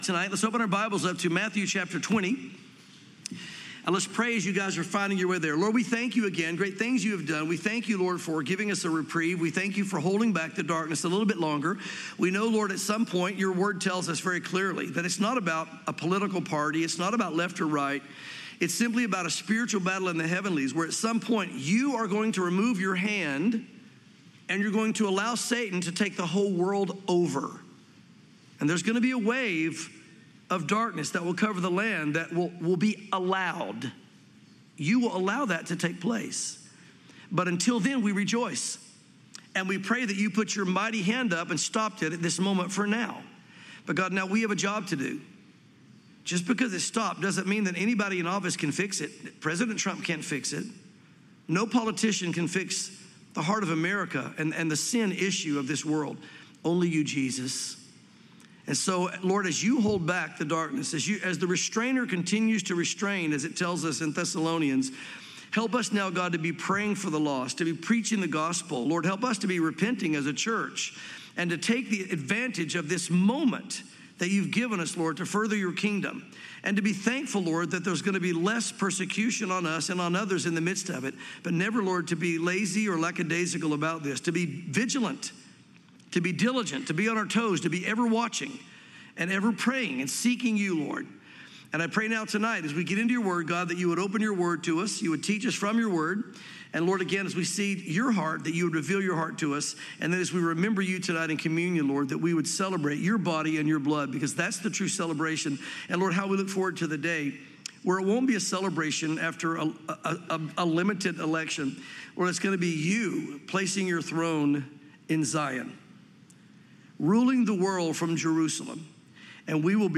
sermons Matthew 20